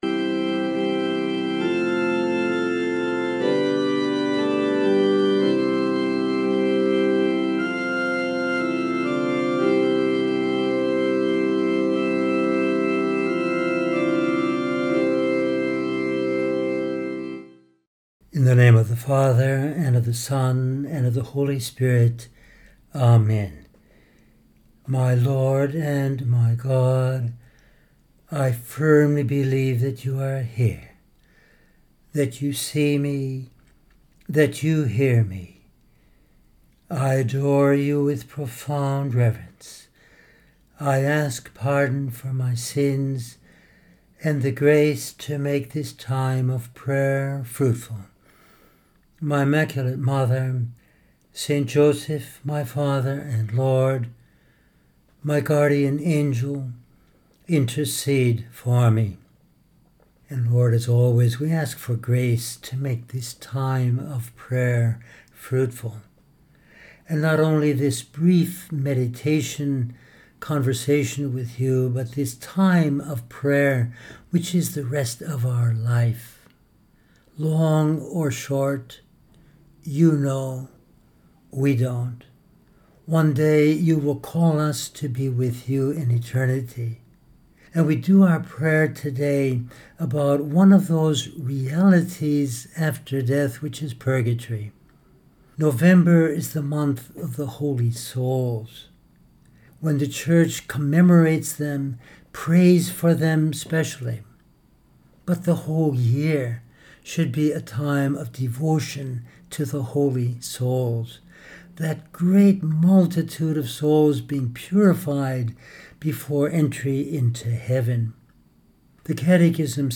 In this meditation we use texts from Scripture, the Catechism and saints to consider how: